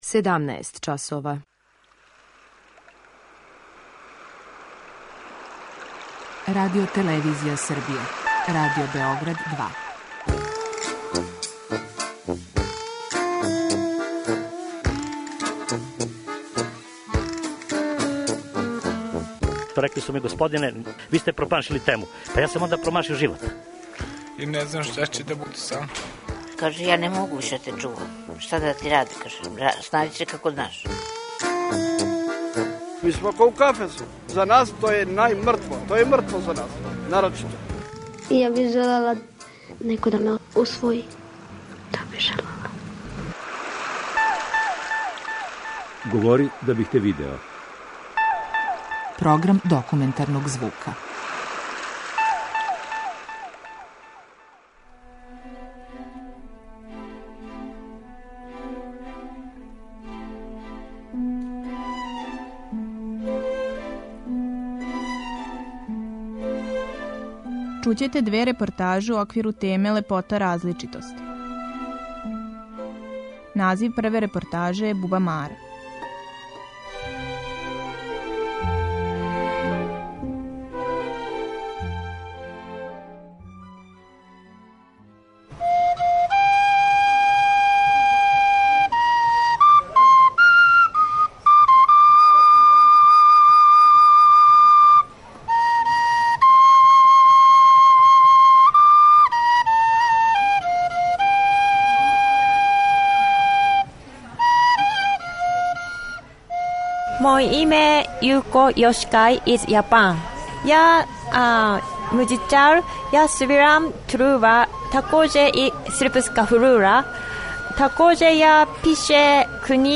У репортажи "Ни на небу ни на земљи", слушаоцима ће се својим аутентичним музичким интерпретацијама представити чланови Вокално-инструменталног састава "Небоград".
преузми : 10.75 MB Говори да бих те видео Autor: Група аутора Серија полусатних документарних репортажа, за чији је скупни назив узета позната Сократова изрека: "Говори да бих те видео".